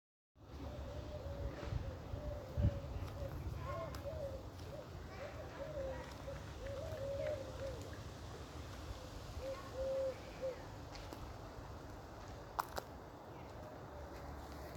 В этот день, 5 августа, я проводил наблюдения в Андреевском парке города Геленджика.
Температура была невысокой, примерно 20-22 градуса, погода была ясной, однако был очень сильный ветер, который, вероятно, затруднил наблюдения.
На обратном пути я смог записать воркование, которое, как выяснилось позднее, издавала кольчатая горлица.